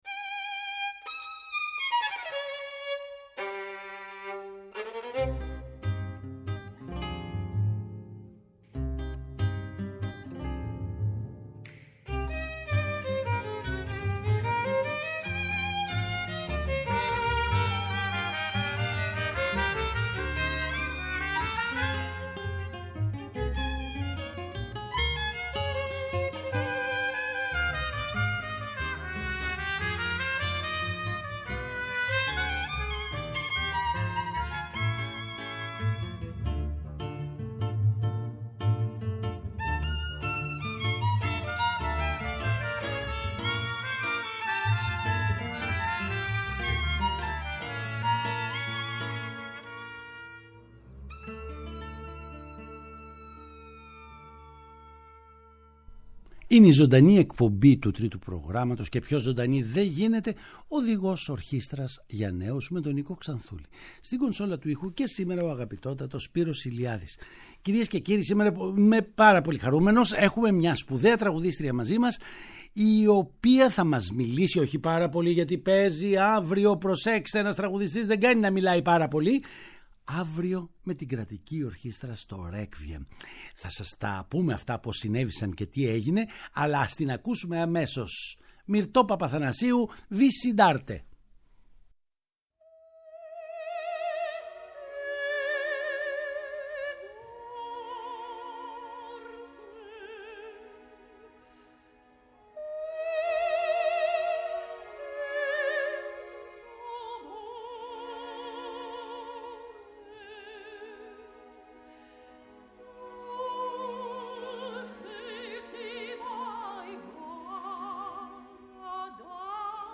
Παραγωγή-Παρουσίαση: Νίκος Ξανθούλης
Παραγωγή-Παρουσίαση: Νίκος Ξανθούλης ΤΡΙΤΟ ΠΡΟΓΡΑΜΜΑ Οδηγος Ορχηστρας για Νεους Συνεντεύξεις Μυρτω Παπαθανασιου